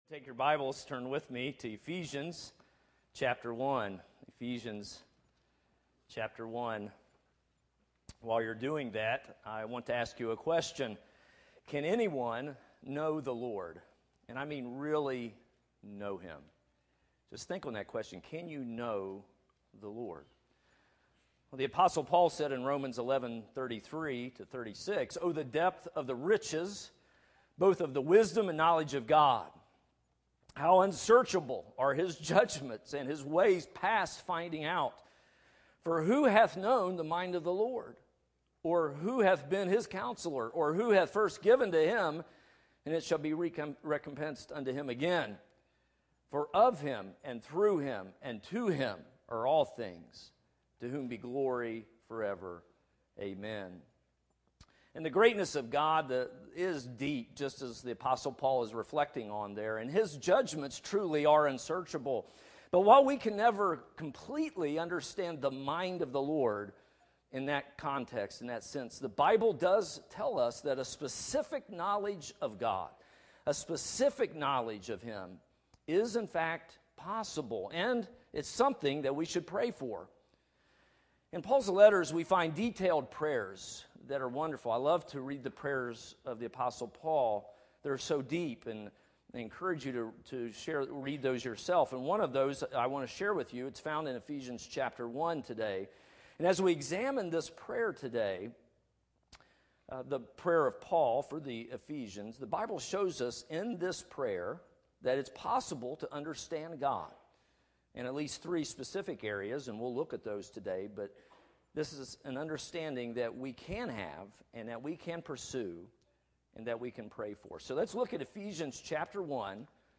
Chapel Message